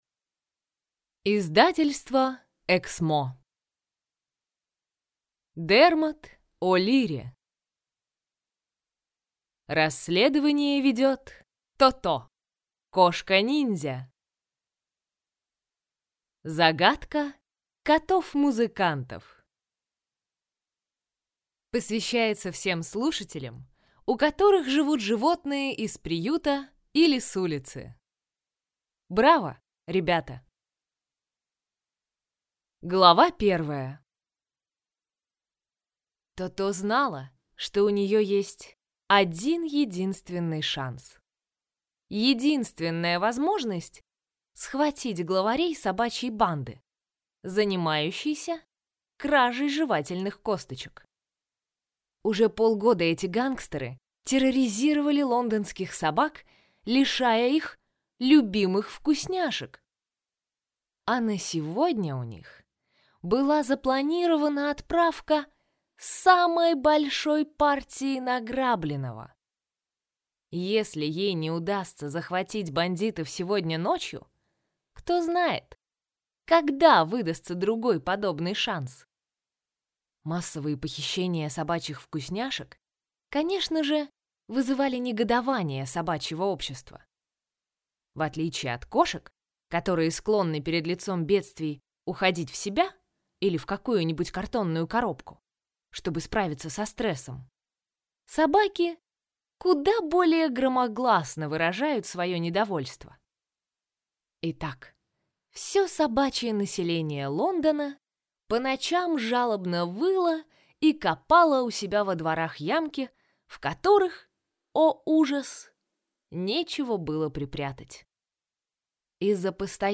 Аудиокнига Загадка котов-музыкантов | Библиотека аудиокниг